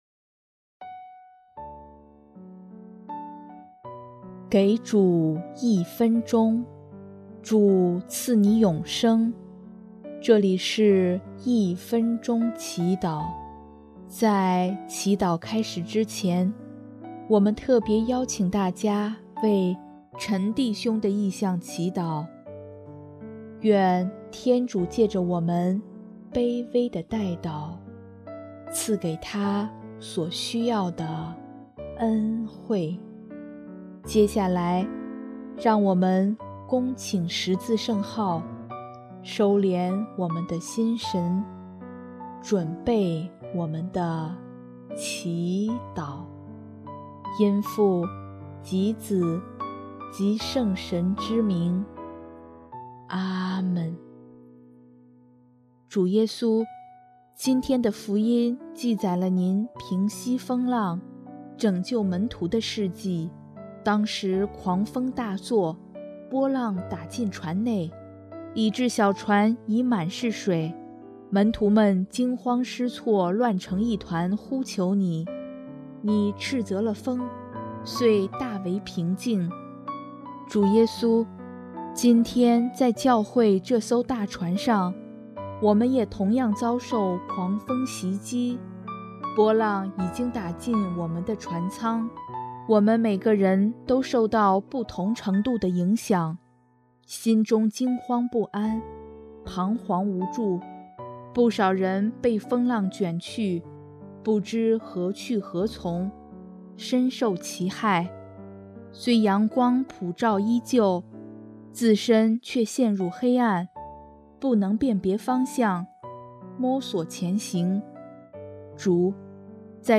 音乐：第四届华语圣歌大赛参赛歌曲《你是我生命的主》